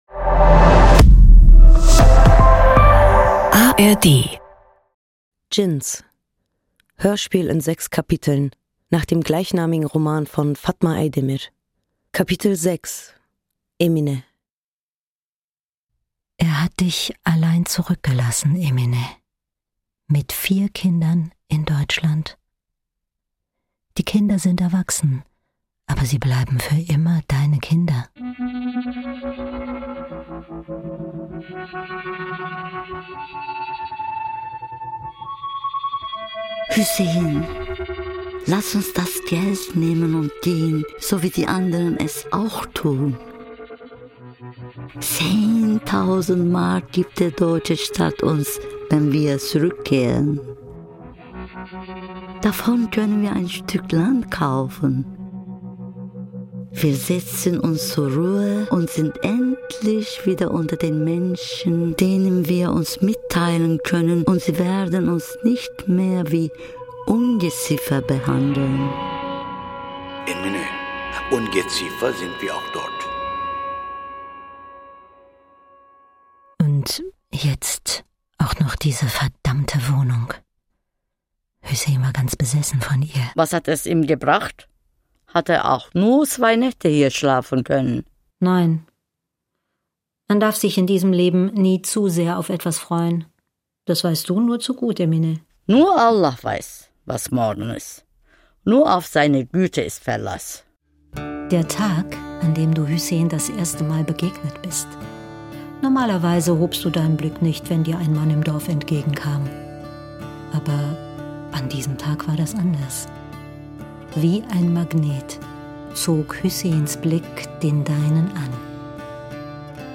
Bevor die Sonne aufgeht, spricht eine Stimme direkt zu Emine.